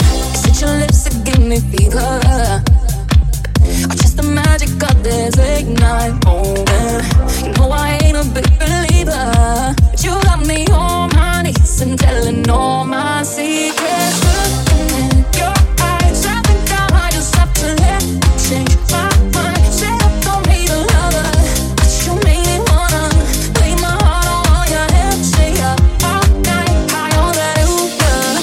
Genere: pop, slap, deep, house, edm, remix